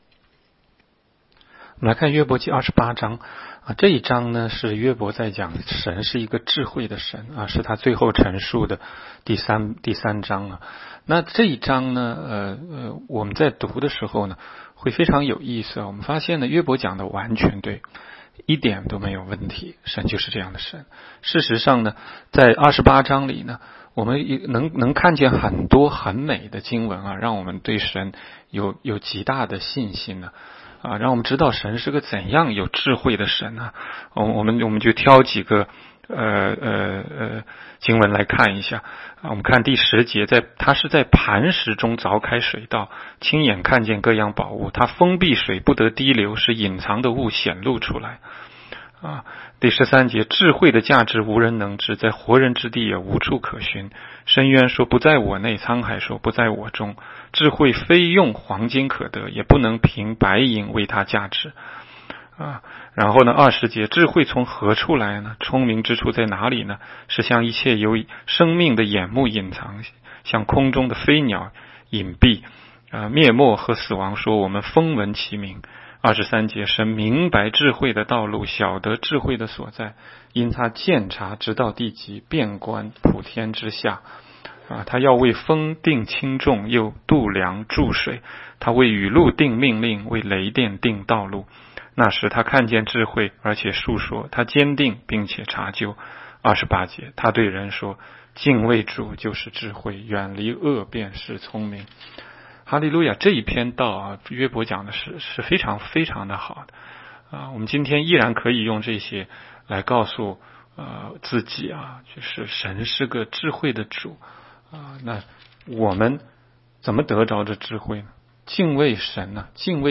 16街讲道录音 - 每日读经-《约伯记》28章